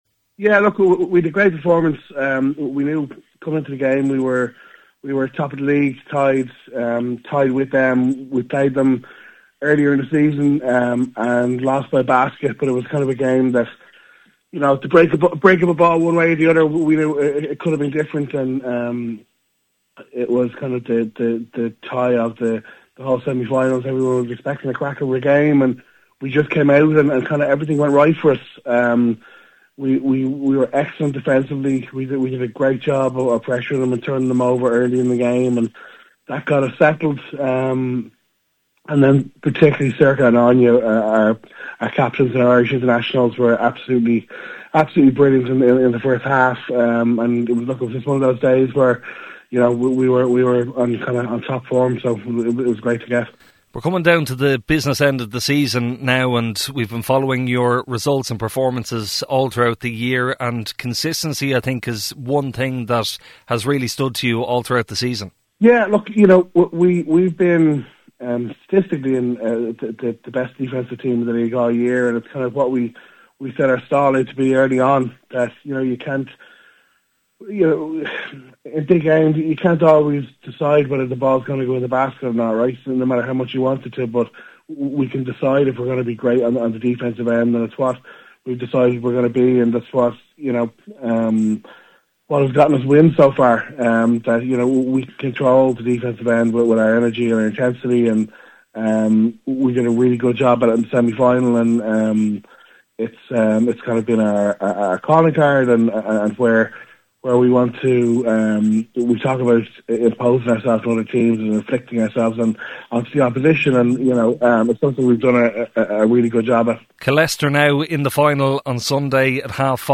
spoke to Kfm ahead of the game.